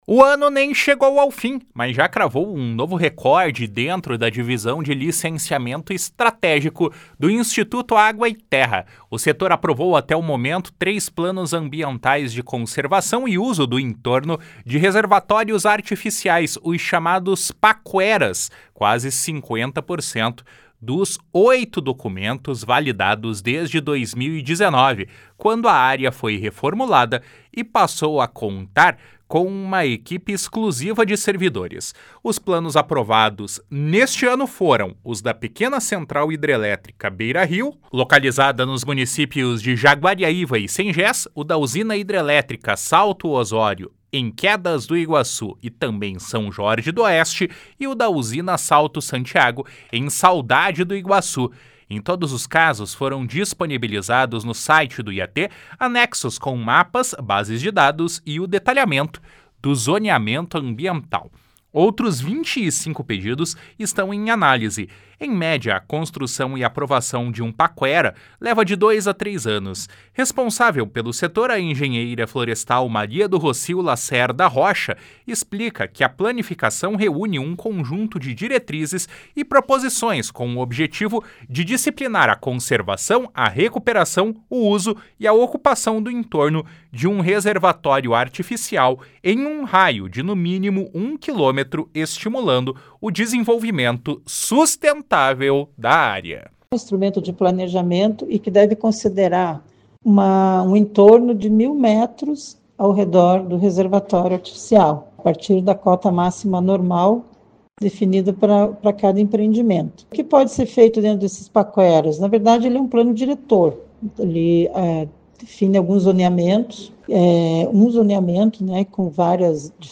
Paralelamente, a divisão está trabalhando na criação de uma instrução normativa específica, regramento que promete dar ainda mais rapidez aos Pacueras analisados. (Repórter